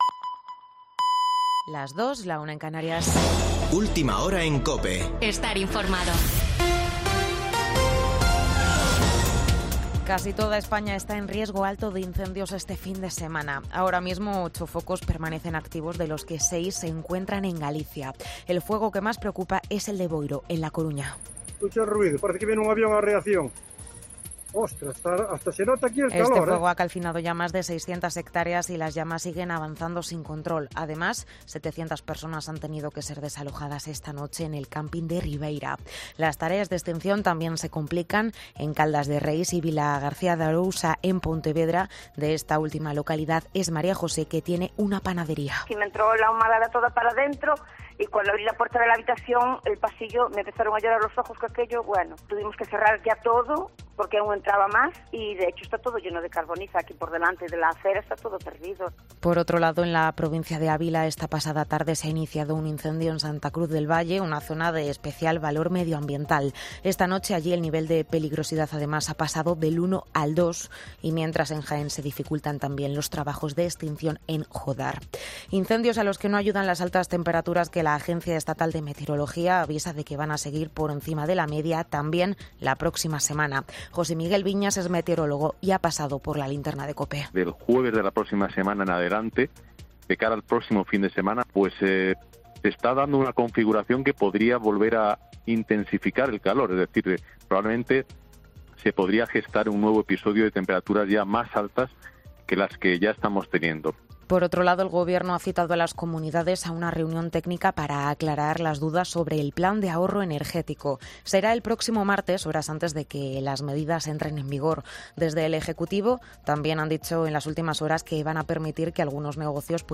Boletín de noticias de COPE del 6 de agosto de 2022 a las 02.00 horas